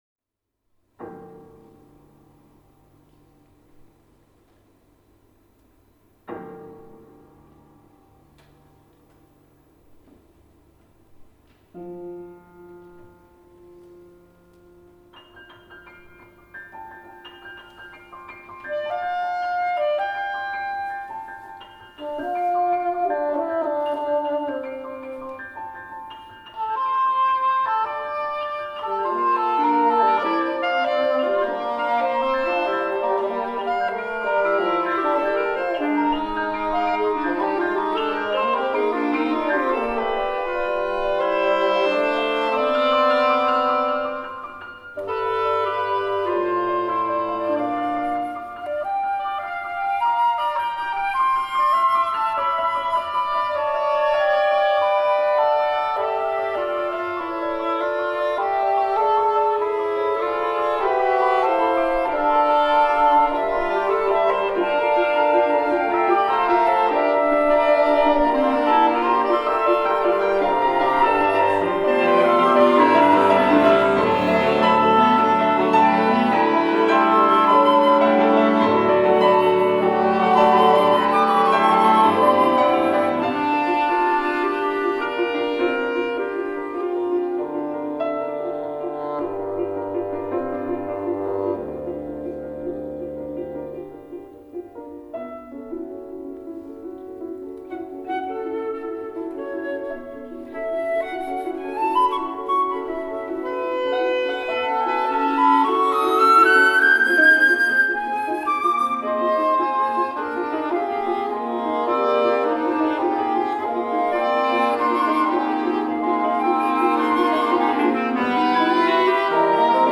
für Holzbläser und Klavier